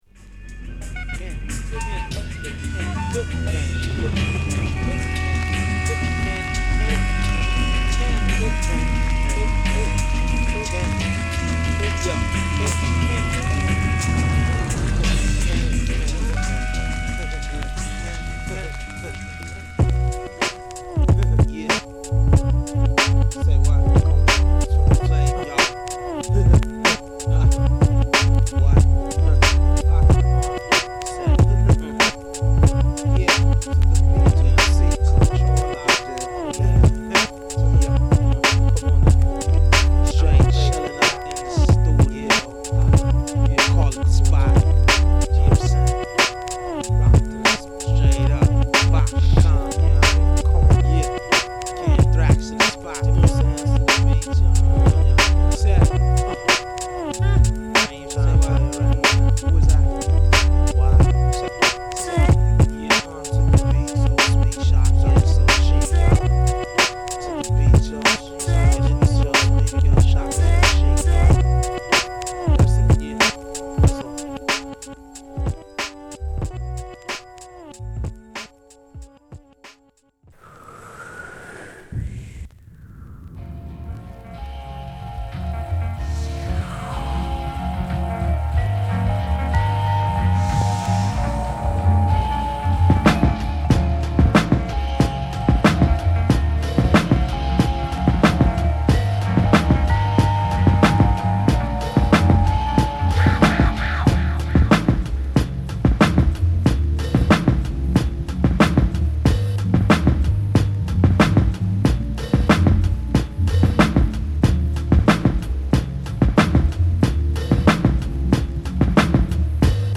ロウでスモーキーなビートを満載！